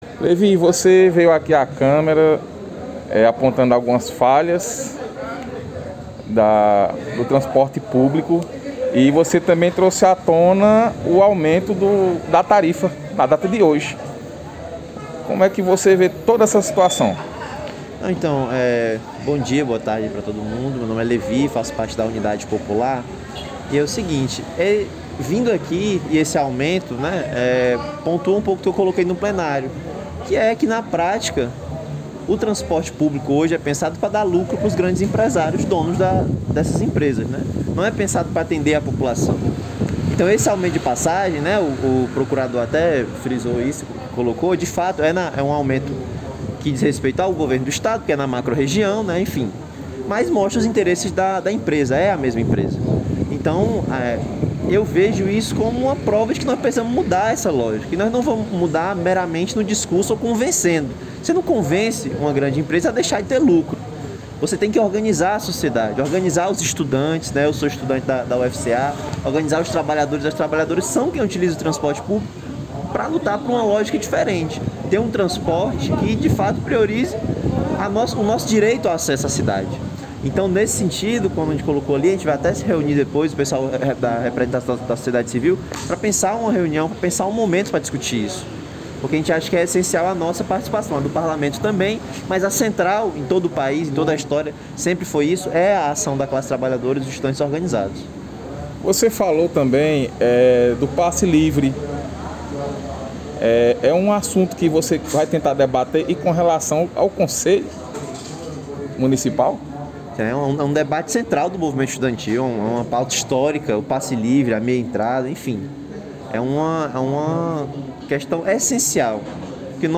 Em entrevista ao Site Miséria